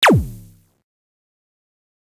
shot.ogg